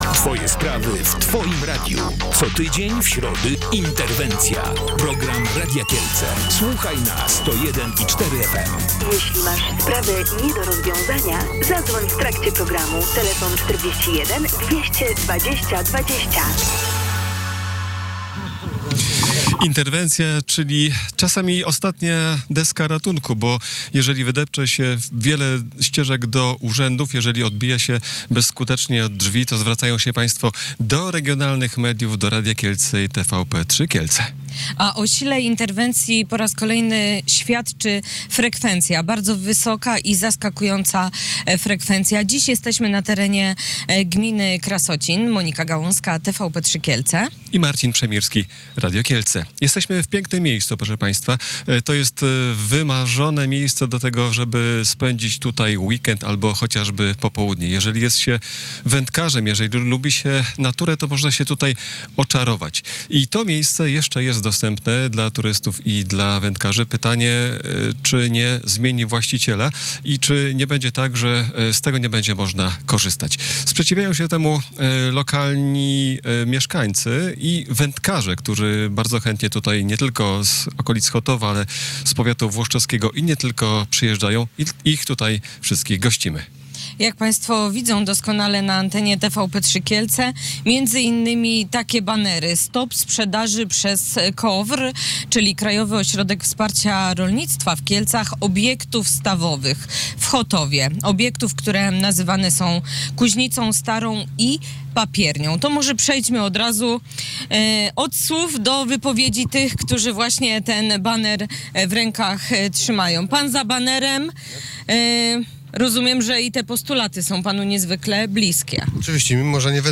14.05.2025. Chotów. Interwencja Radia Kielce.